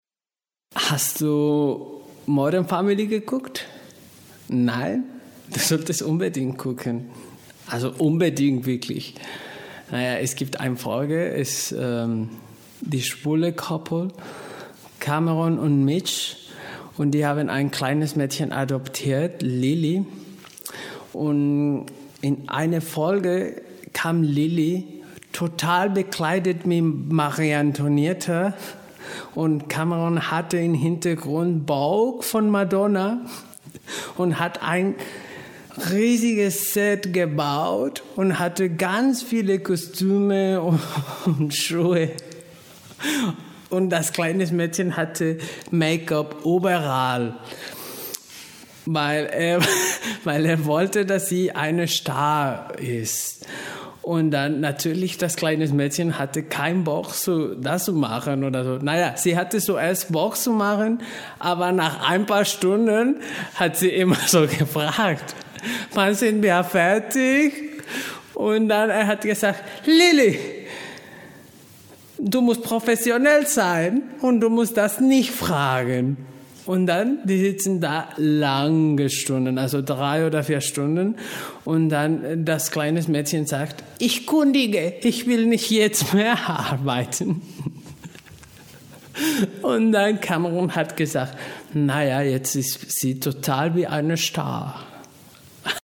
Sprecher, Werbesprecher